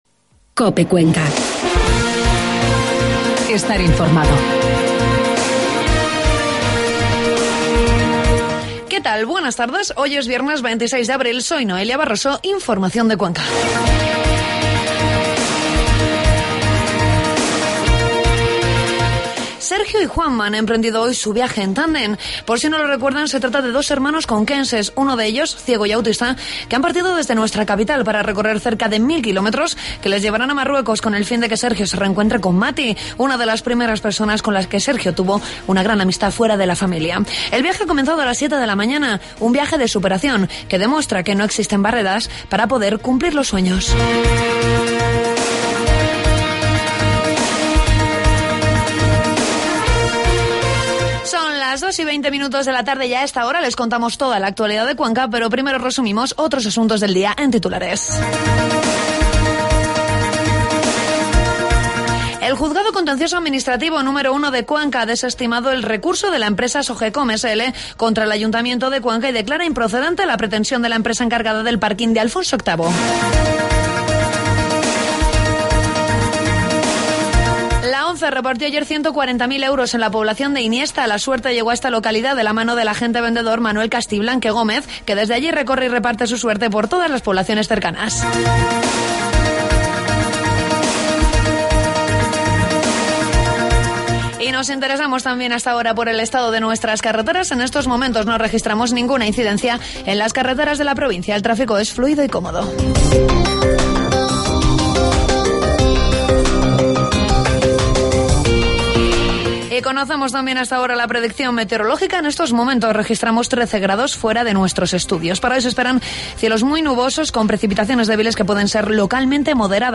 Toda la información de la provincia de Cuenca en los informativos de meidodía de COPE